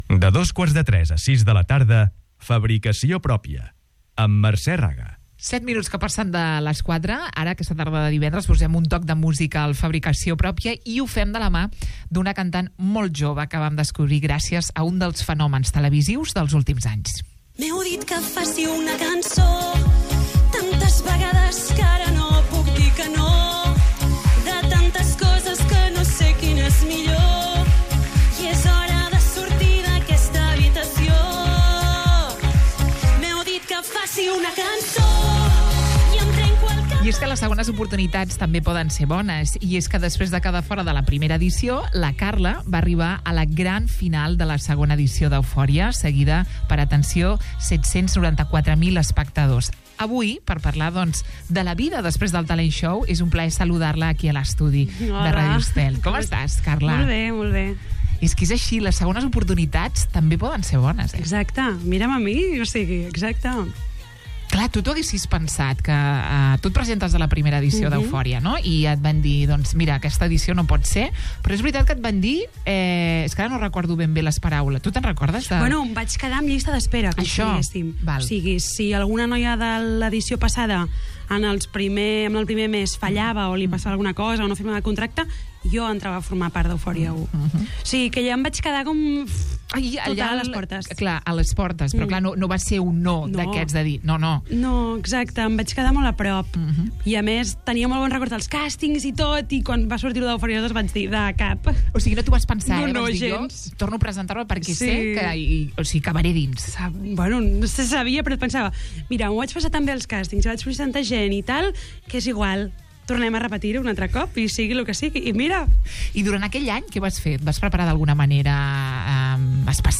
s'asseu als micròfons del Fabricació Pròpia i descobrim la seva altra gran passió